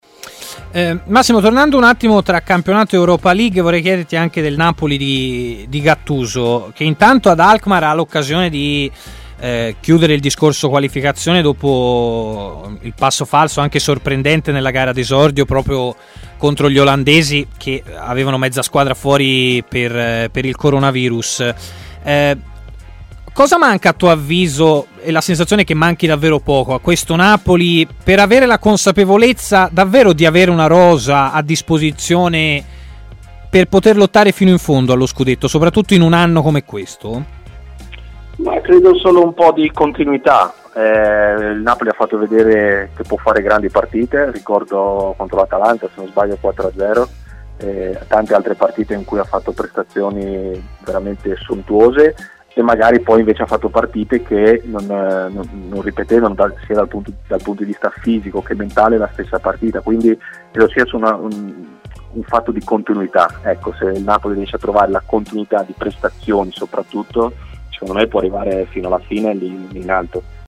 L'ex centrocampista Massimo Donati, doppio ex della sfida di Europa League tra Milan e Celtic, è intervenuto in diretta ai microfoni di TMW Radio